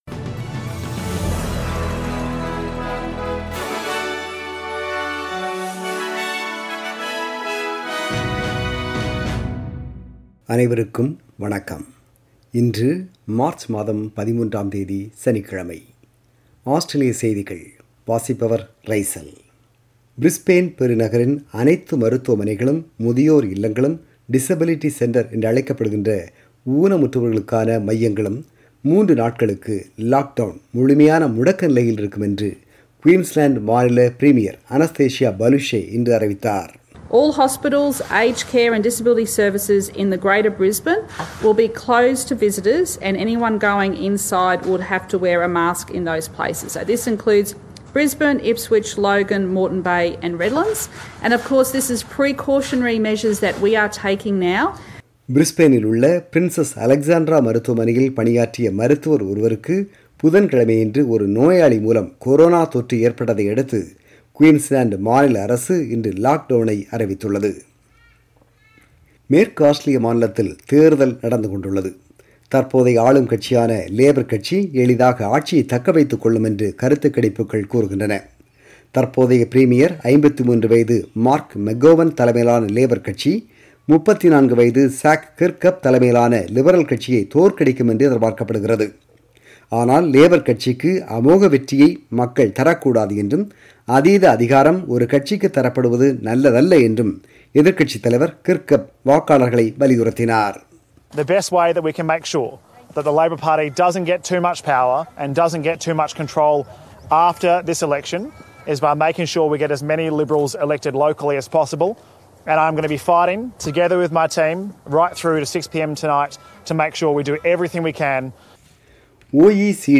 Australian News: 13 March 2021 – Saturday